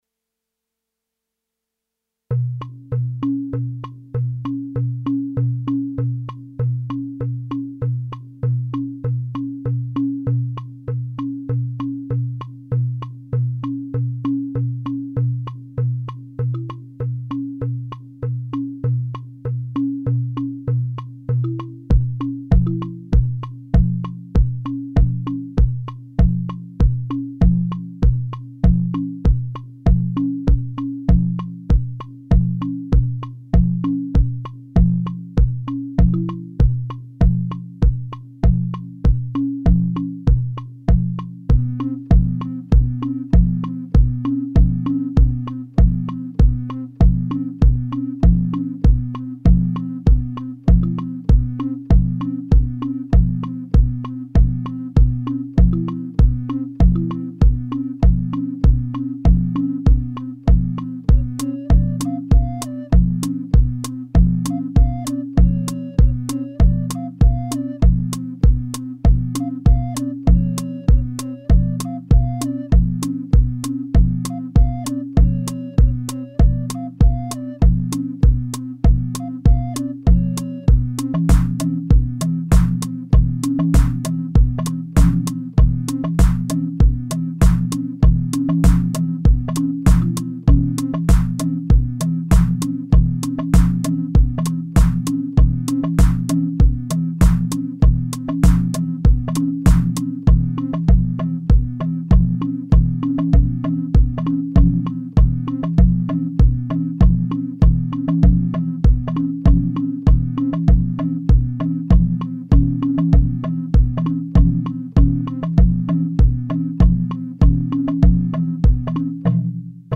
Everything sounded like garbage so switched the midi drum sequence to controlling proteus (kalimba sound) switched a couple notes around added the simplest beat with 909 samples and a few notes from recorders that were within reach